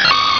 Cri de Balignon dans Pokémon Rubis et Saphir.